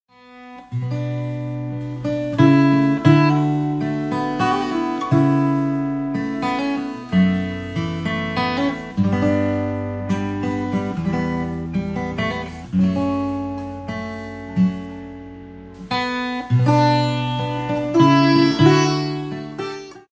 chitarra acustica e loops
chitarra 7 corde